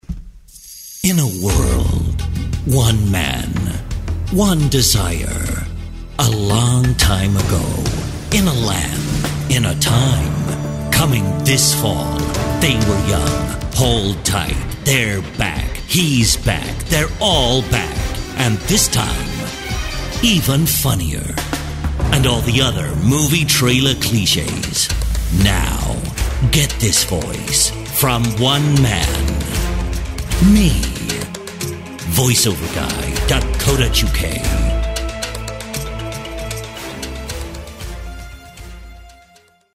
The Movie Trailer voice
The style has been around for years.
The Big Trailer Voice that makes you wanna watch that film or… buy YOUR Product!
Despite being British I can give you an American and English Movie Trailer Voice.